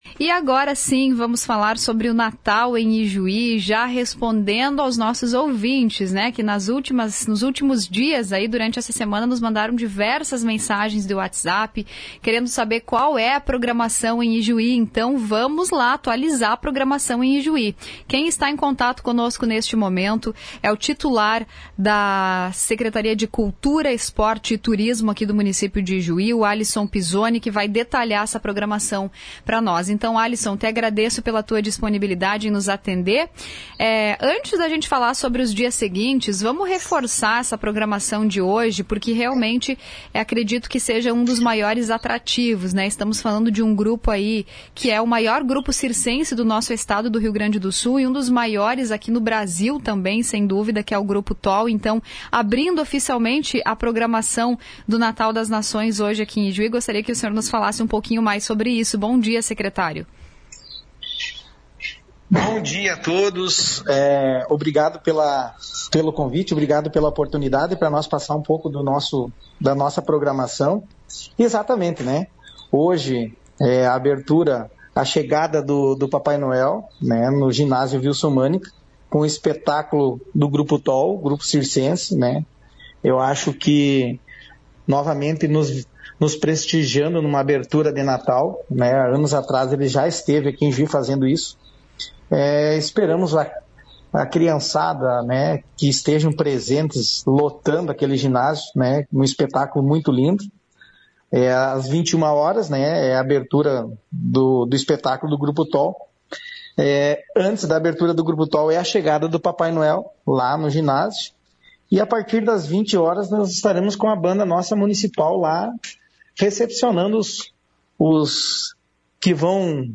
Em entrevista à Rádio Progresso nesta quinta-feira (12), o secretário de Cultura, Esporte e Turismo de Ijuí, Alisson Pizzoni, detalhou a programação do Natal das Nações 2024, cujas atividades começam hoje seguem até o dia 23 de dezembro.
ENTREVISTA-ALISSON-PIZZONI.mp3